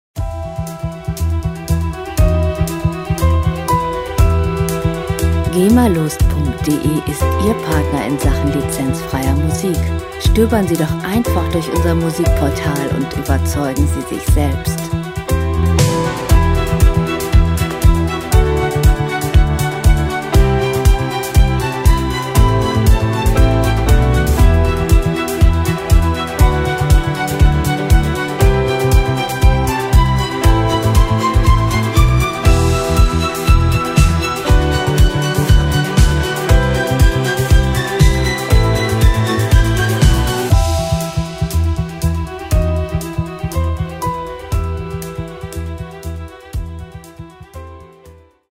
Klassik Pop
Musikstil: Crossover
Tempo: 119 bpm
Tonart: E-Moll
Charakter: aufgewühlt, bewegt
Instrumentierung: Streicher-Ensemble, Piano, E-Bass, Drums